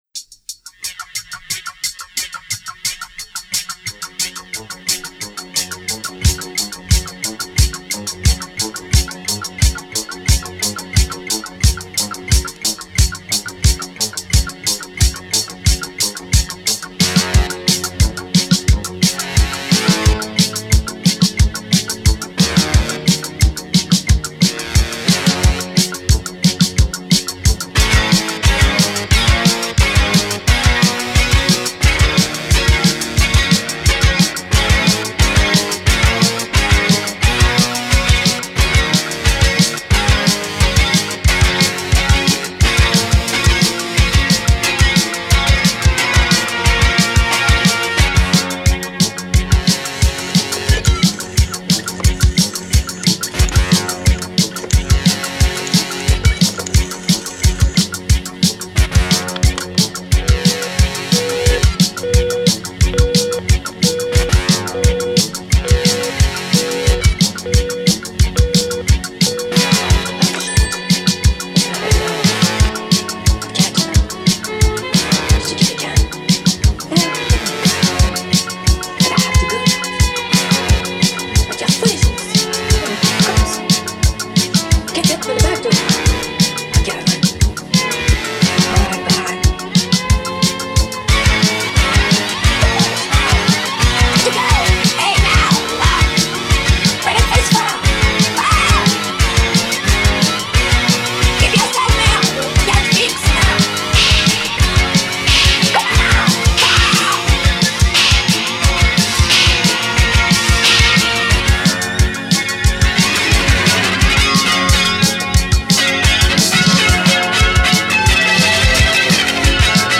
French synth pop